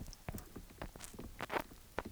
Lady crab spectrogram Spectrogram of Lady crab, Ovalipes ocellatus sounds recorded in Cotuit Bay on Cape Cod, MA on 8 June 2014. Sound recorded as swimming crab ran into hydrophone array.
Ovalipes ocellatus 8June2014_Cotuit.wav